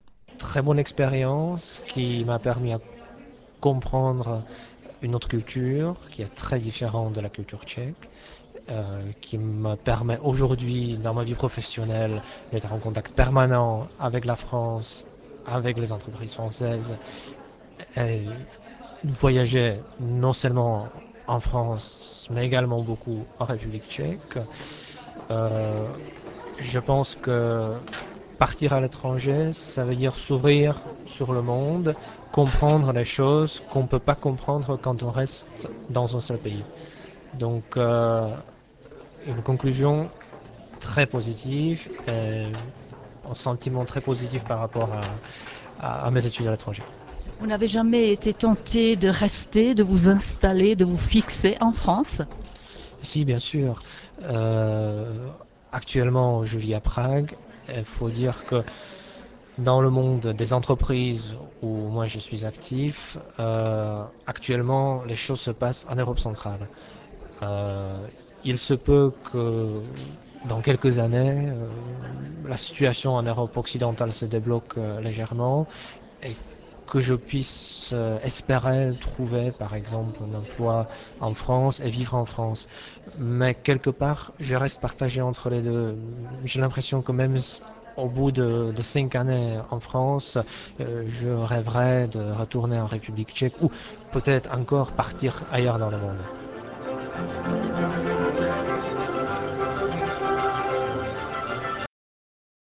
J'espère que le choix des extraits de quelques interviews intéressantes que mes collègues ont réalisées au cours de cette année fera plaisir à vous tous qui êtes actuellement à l'écoute.